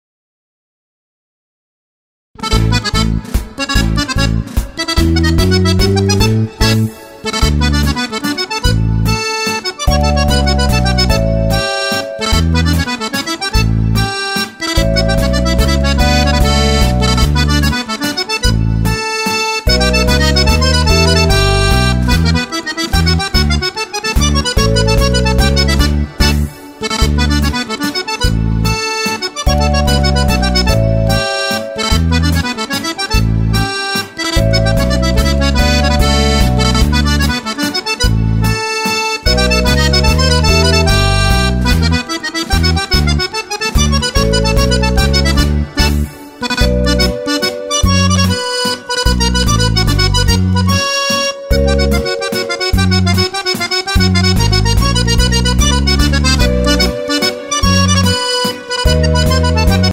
Playback - audio karaoke für Akkordeon